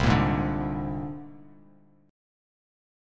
C7sus2#5 Chord